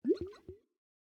assets / minecraft / sounds / mob / axolotl / idle3.ogg